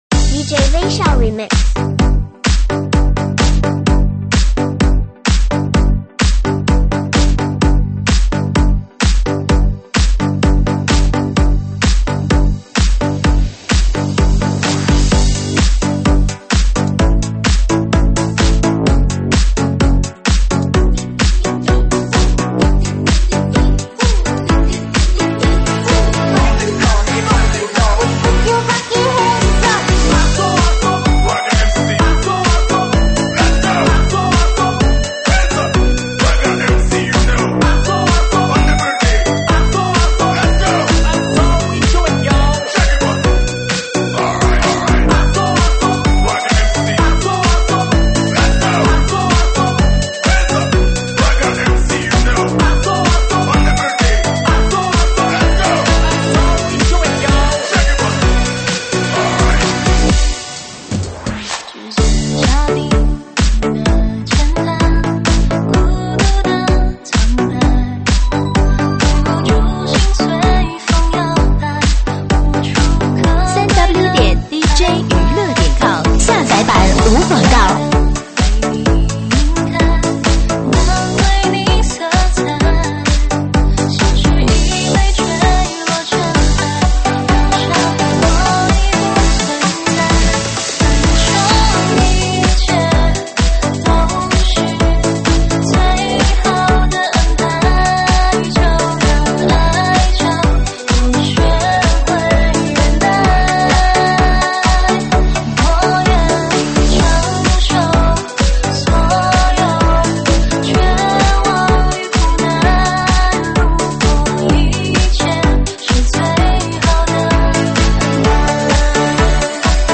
现场串烧